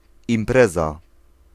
Ääntäminen
France: IPA: [ɛ̃n‿evɛnmɑ̃]